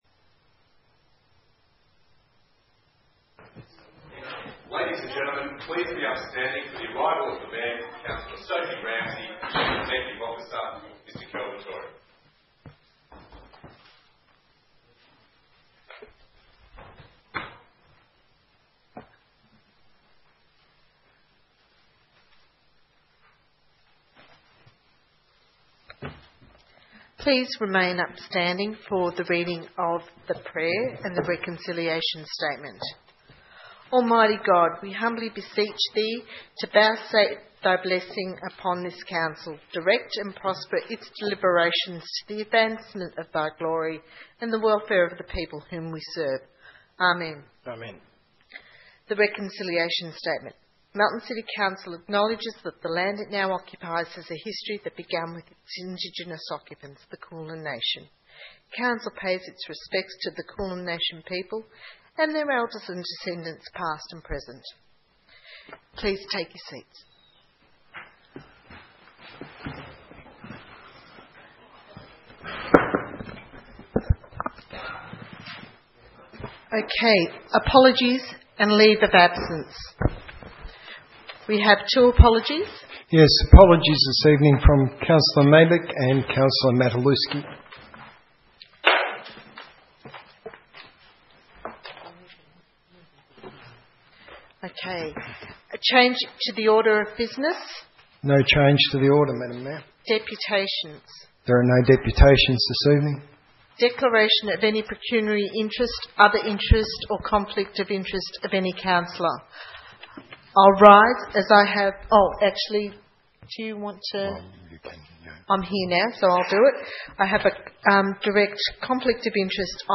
16 December 2014 - Ordinary Council Meeting